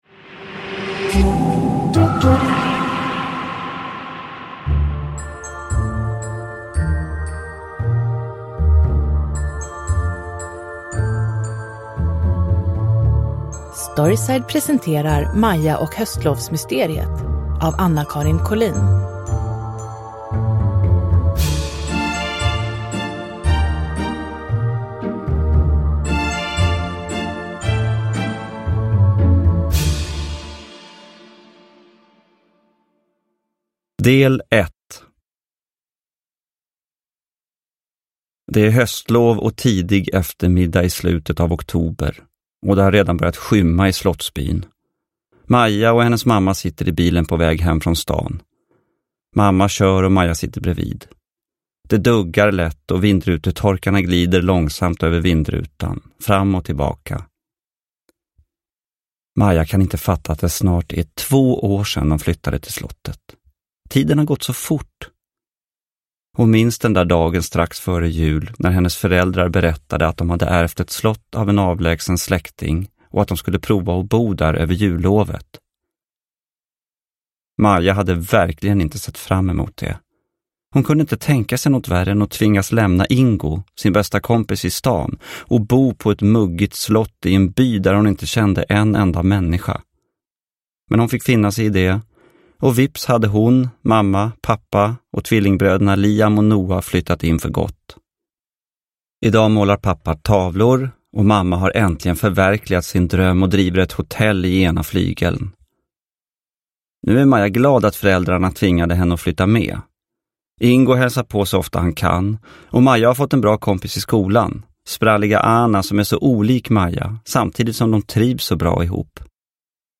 Maja och höstlovsmysteriet – Ljudbok – Laddas ner
Uppläsare: Gustaf Hammarsten